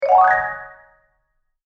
演出 （102件）
マリンバグリッサンド1.mp3